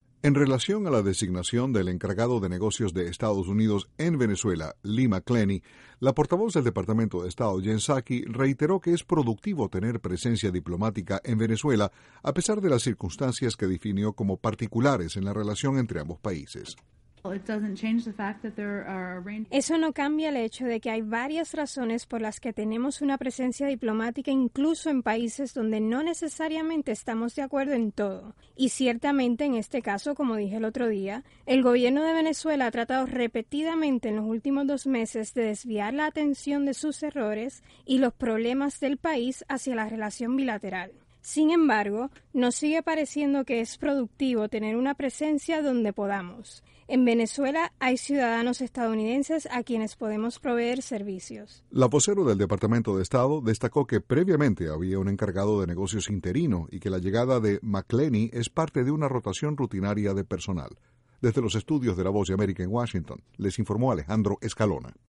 INTRO: Estados Unidos indicó nuevamente este miércoles que es productivo tener una presencia diplomática en Venezuela. Desde la Voz de América en Washington informa